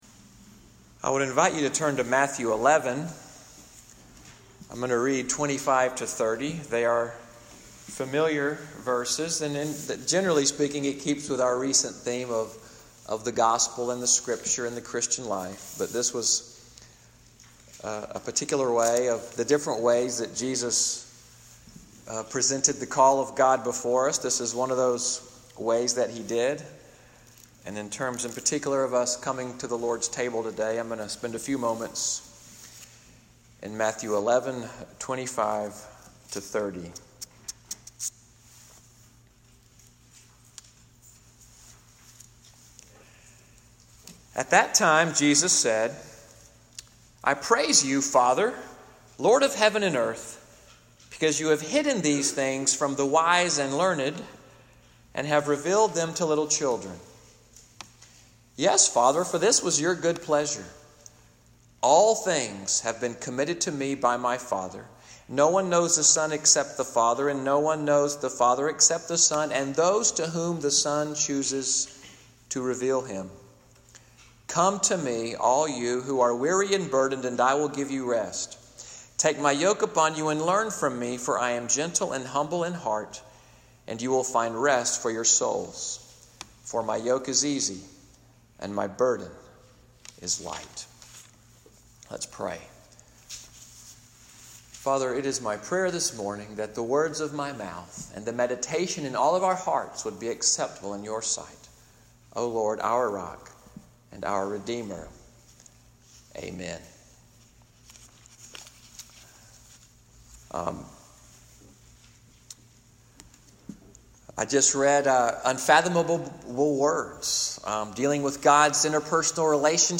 Morning Worship at Little Sandy Ridge Presbyterian Church in Fort Deposit, Alabama, audio from the Communion Meditation, “The Call of Christ,” (20:37) July 1, 2018.